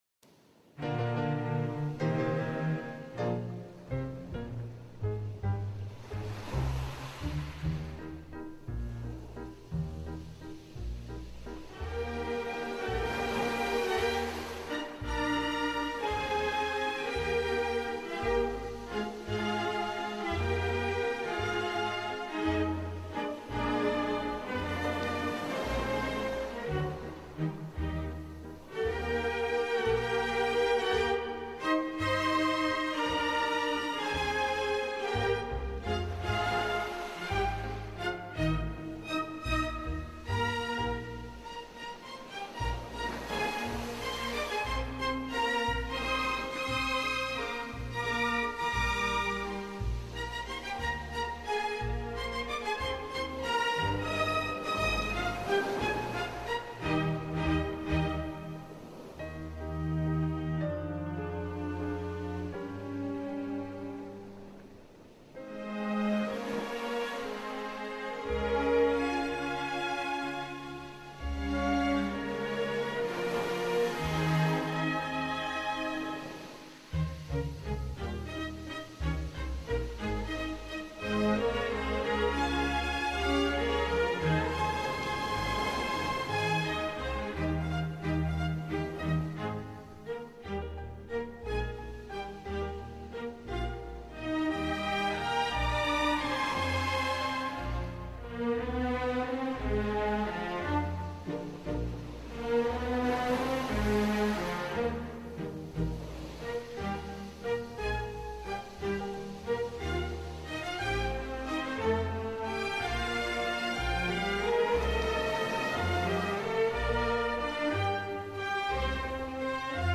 Strauss – Classical Instrumentals for Mental Clarity